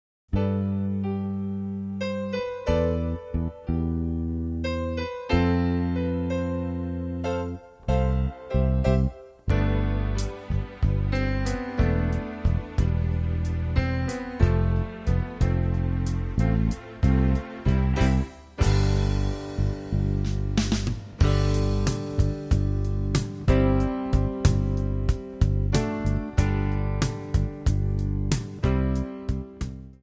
Gb
Backing track Karaoke
Pop, 1980s